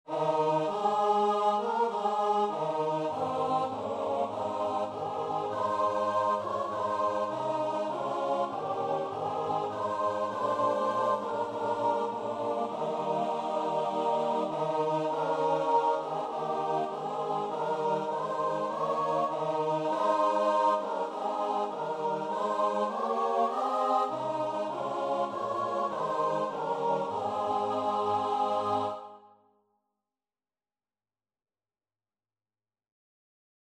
4/4 (View more 4/4 Music)
Choir  (View more Intermediate Choir Music)
Classical (View more Classical Choir Music)